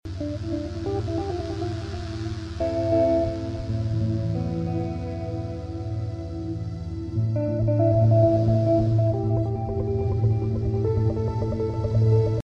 Music for conscious living & deeper connection.